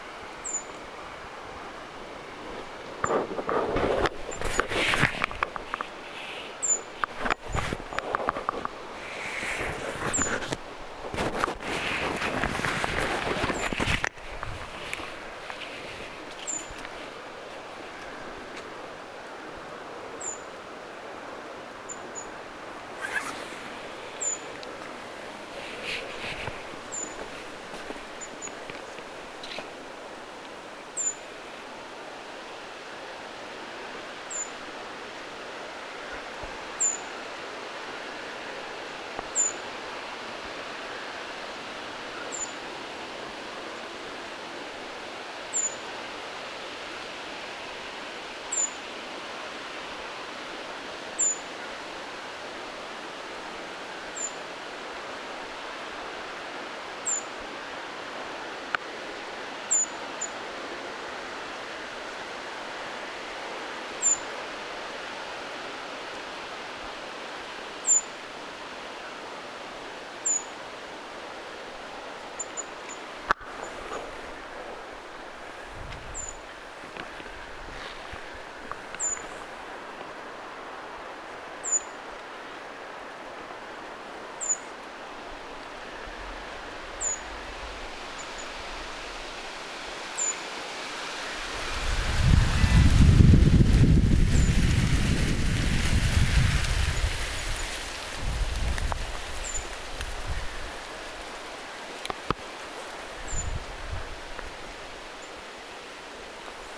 ti-puukiipijä,
kuvien lintu, ääntelee
kuvien_pitkakyntinen_ti-puukiipijalintu_aantelee.mp3